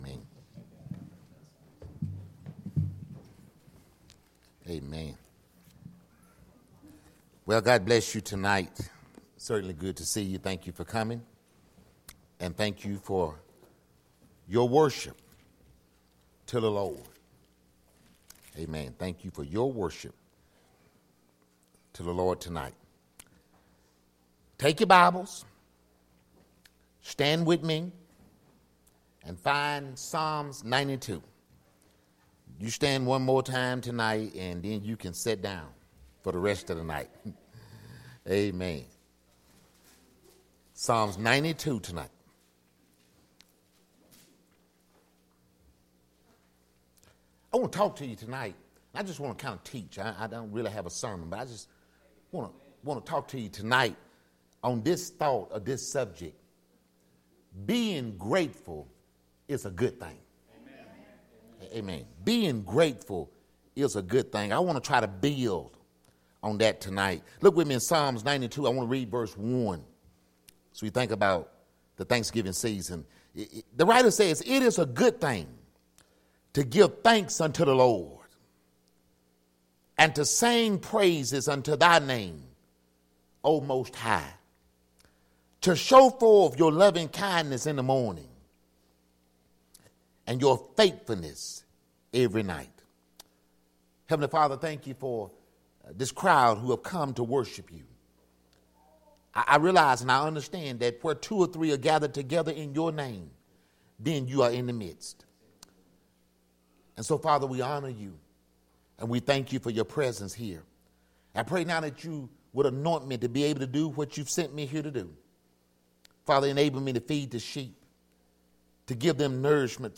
Solid Rock Baptist Church Sermons
Evening service!